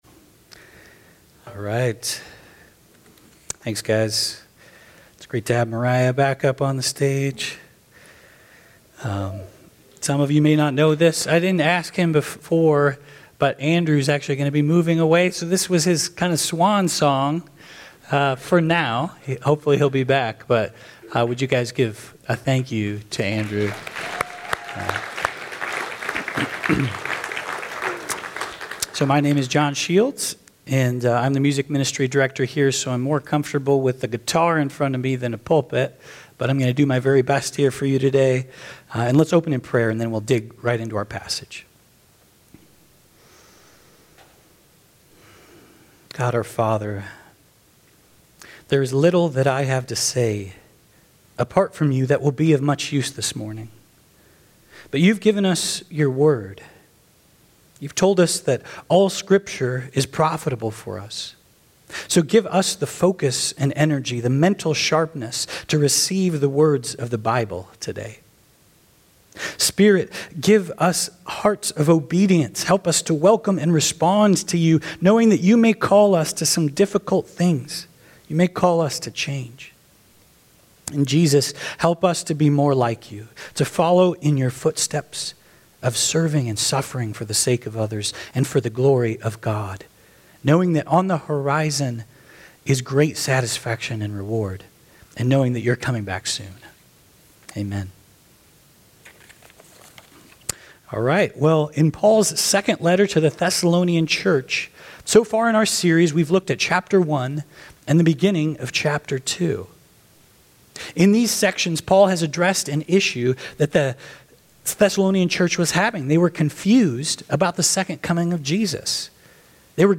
I’m not as comfortable with a pulpit in front of me as I am with a guitar in front of me, but I’ll do my best here.